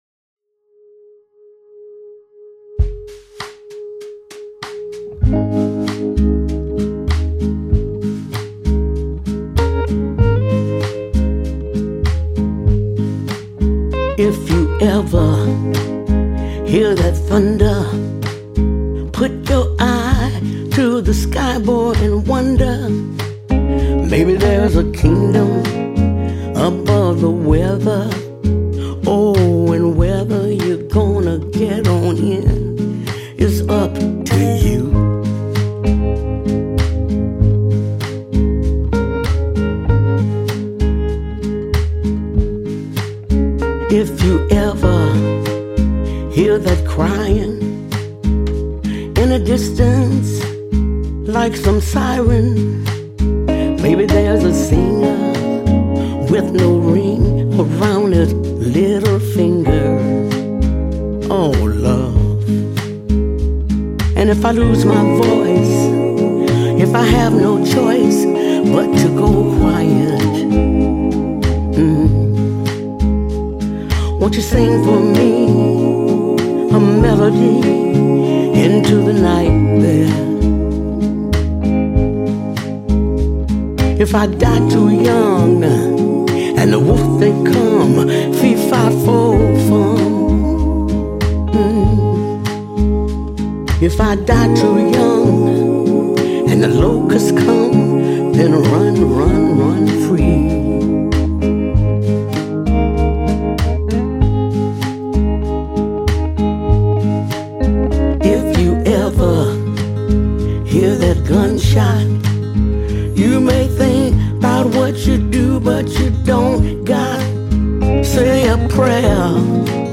Жанр альбома: соул, госпел, блюз.